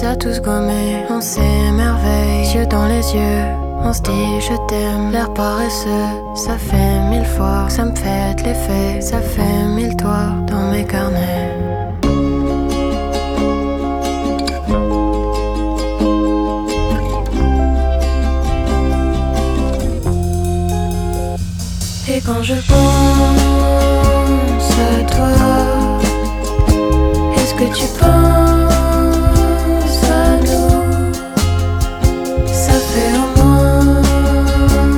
Жанр: Поп музыка
French Pop